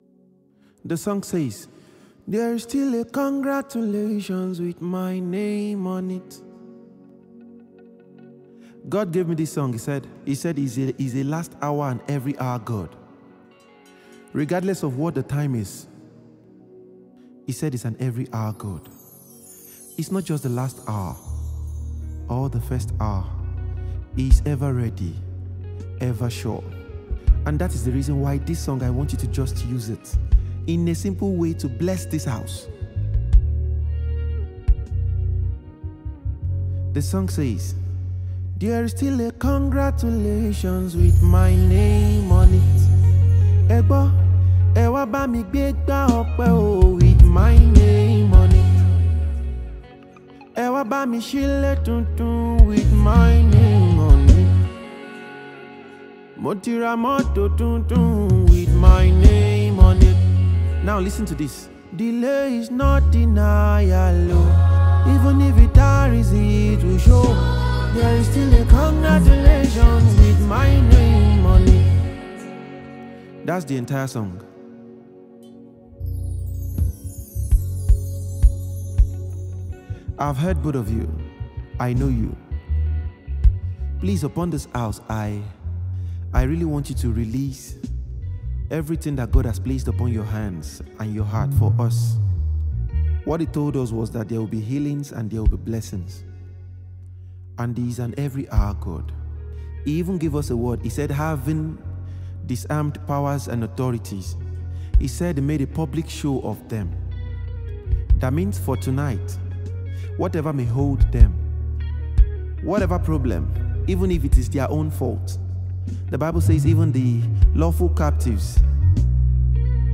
gospel
soul-lifting new single
gospel anthem
uplifting melodies